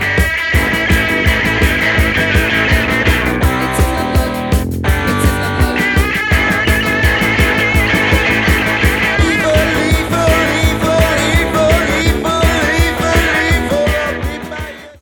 at Air Studios in London in 1976/1977